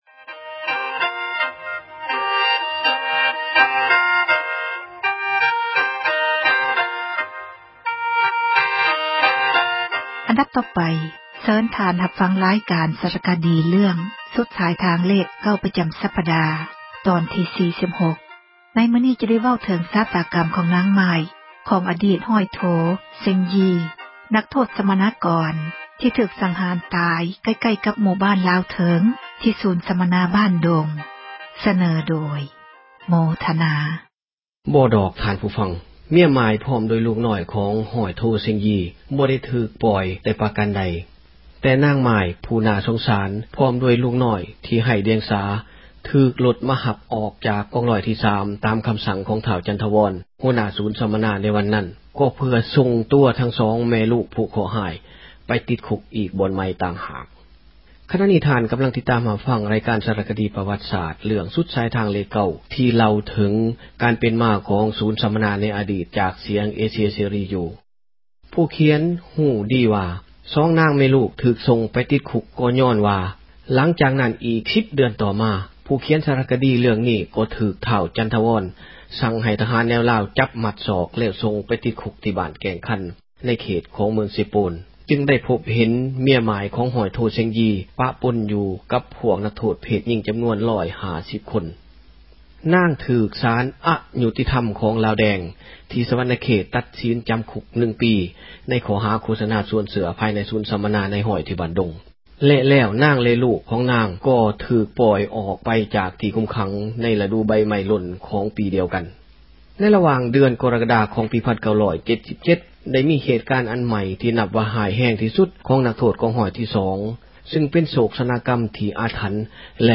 ເຊີນທ່ານຮັບຟັງ ຣາຍການ ສາຣະຄະດີ ເຣື້ອງ ”ສຸດສາຍທາງເລຂ 9” ປະຈໍາສັປດາ ຕອນທີ 46.